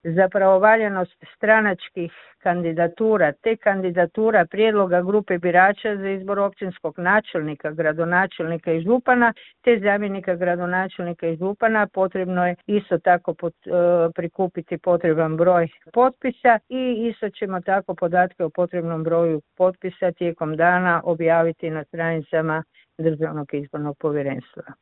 Rokovi počinju teći od 16. travnja u ponoć, a prijedlozi kandidacijskih lista i kandidatura potom moraju prispjeti nadležnom izbornom povjerenstvu u roku od 14 dana od dana stupanja na snagu Odluke o raspisivanju izbora, rekla je u razgovoru za Media servis potpredsjednica Državnog izbornog povjerenstva Vesna Fabijančić Križanić.